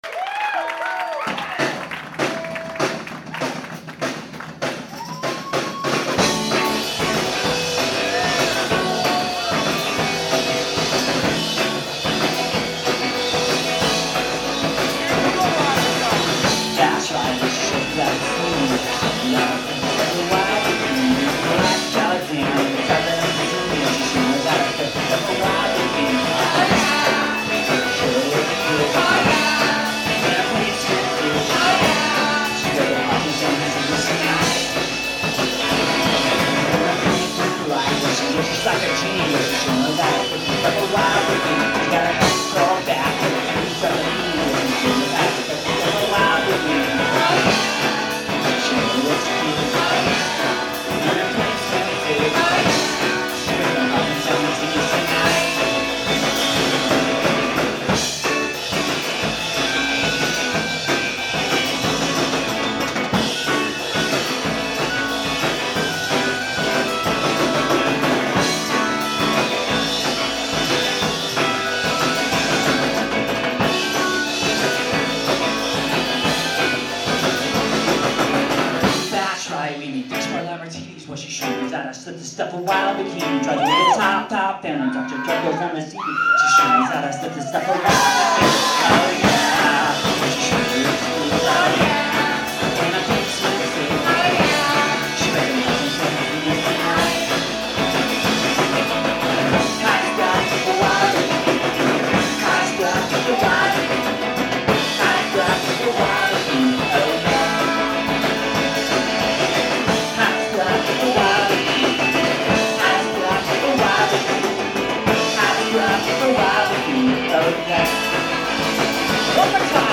Today we have a live recording of the song